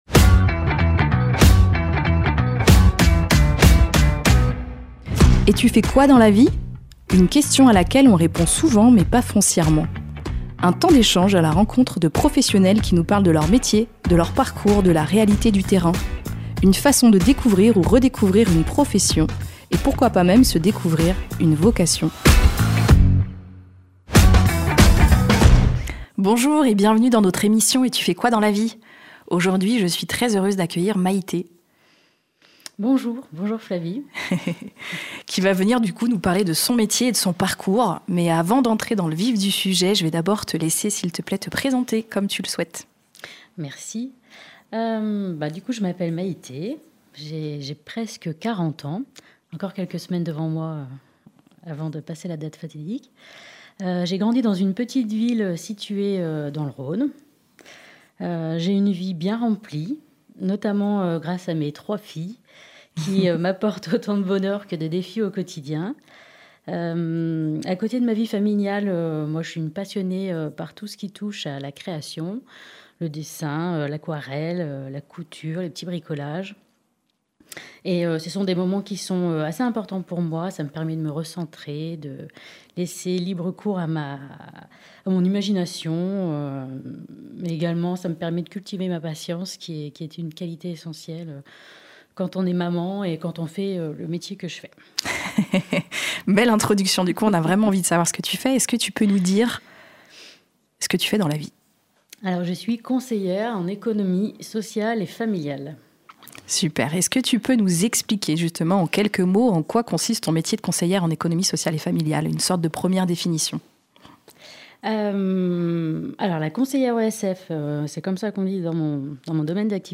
Découvrez des conversations sincères avec des professionnels authentiques qui sauront, qui sait, éveiller chez vous, une ou des vocations.